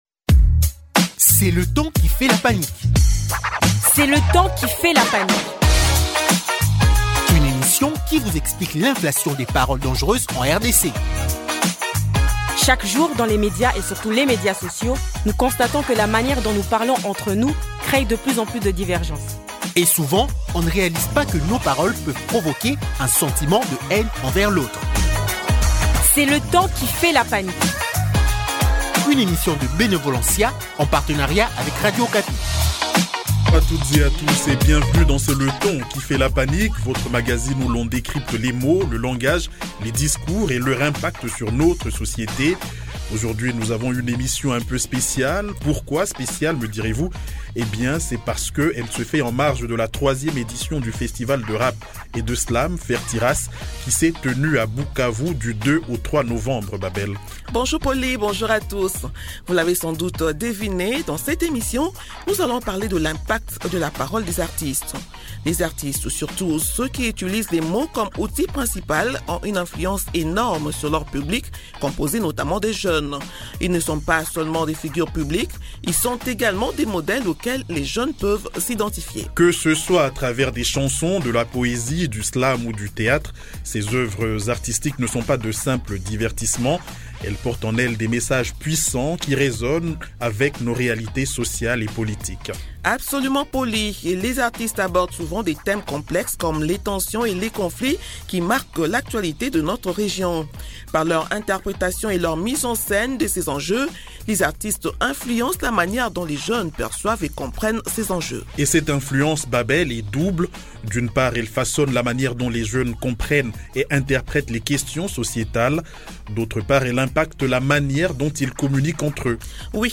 Pour en parler, deux artistes qui ont pris part à ce festival :